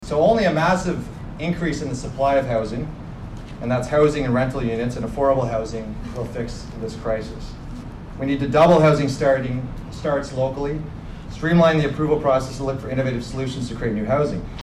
The Belleville Chamber’s breakfast meeting was held at Sans-Souci in the Downtown District.